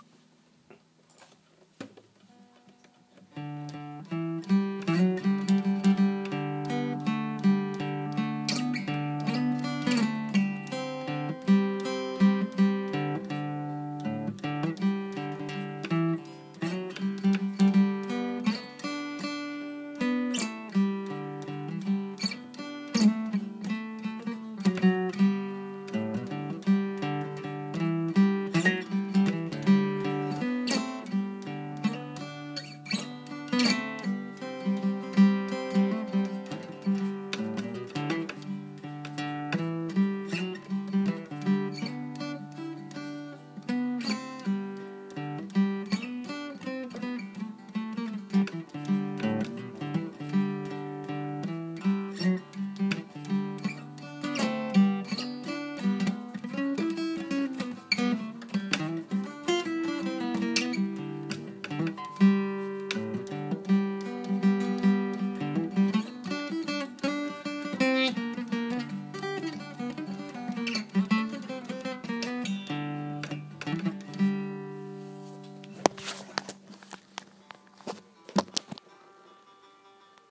This is from my practice from the last 3 or 4 days.
Nice!! Very good tone!
And probably the dirt gives the different muted type sound.
I think it sounds great! love the guitar sound, unfortunately i can’t play the guitar at all …